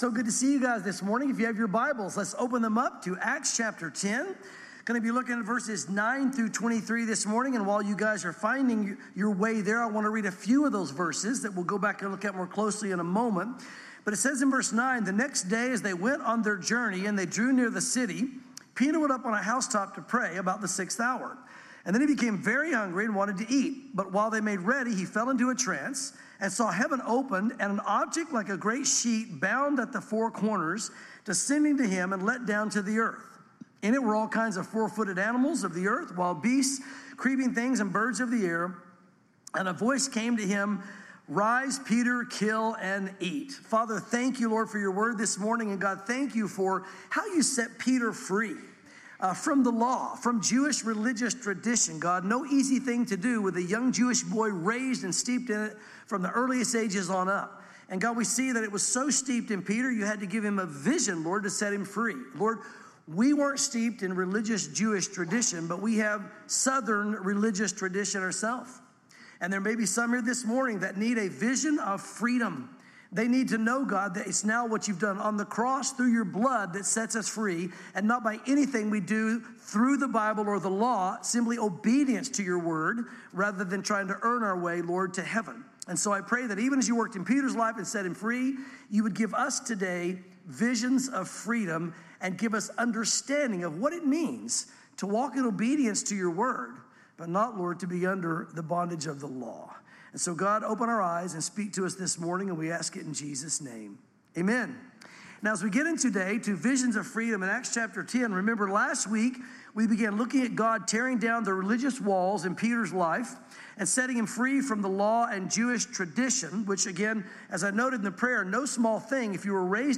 sermons Acts 10:9-23 | Visions of Freedom